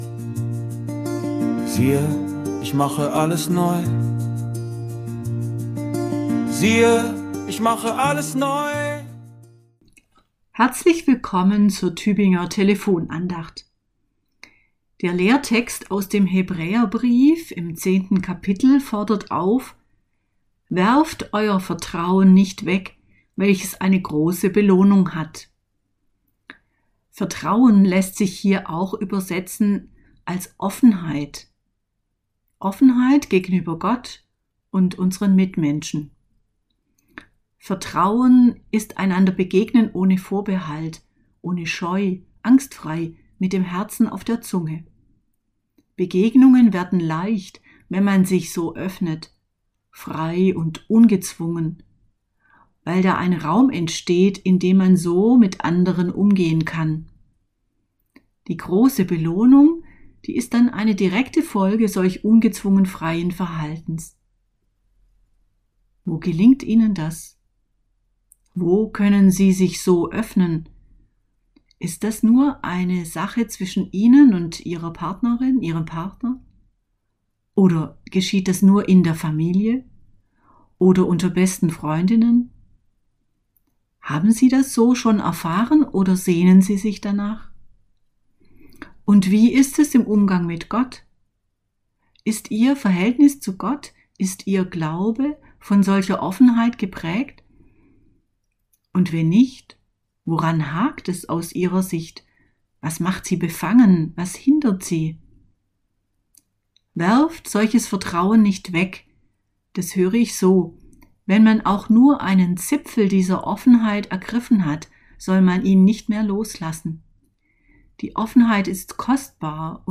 Andacht aus dem Januar